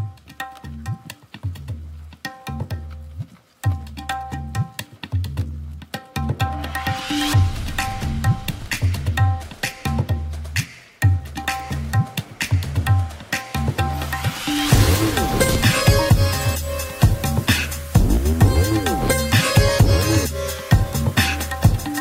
Category: Tabla Ringtones